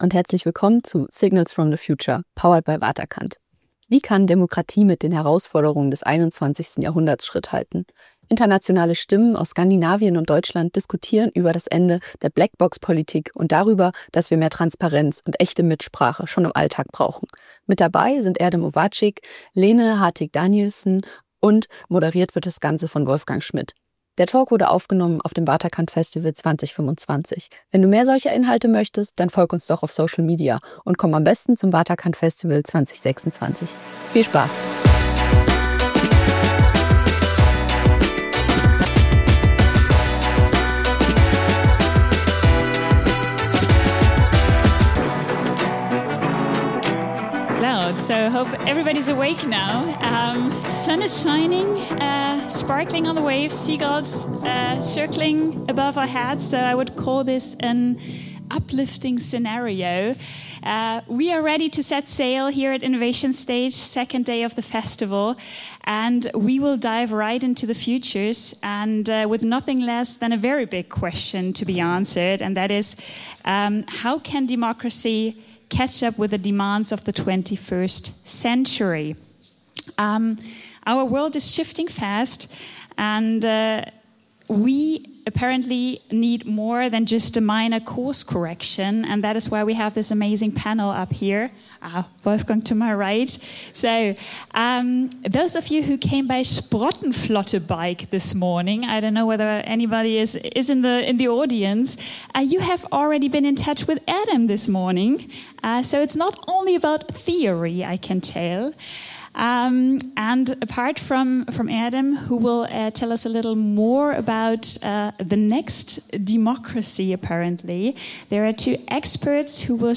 This conversation ranges from practical reforms in Danish neighborhoods to bold ideas inspired by tech and systems thinking — and asks what it really takes to rebuild public trust in the face of global and local challenges.